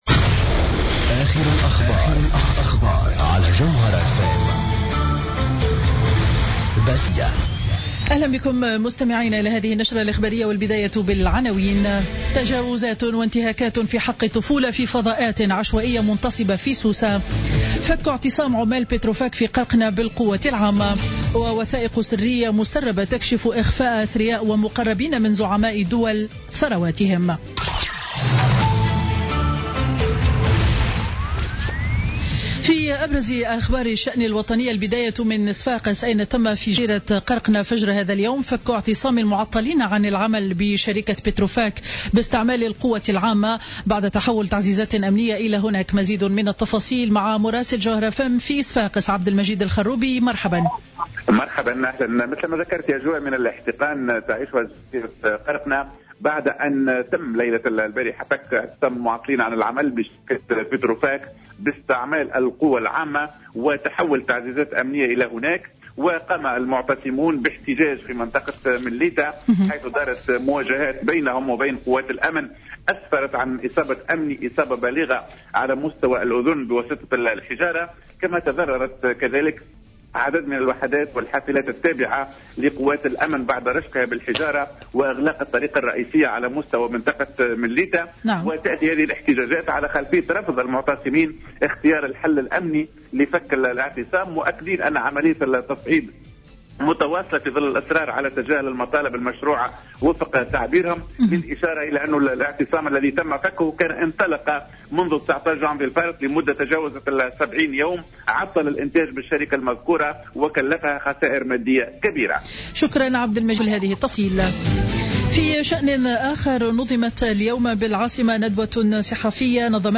نشرة أخبار منتصف النهار ليوم الاثنين 4 أفريل 2016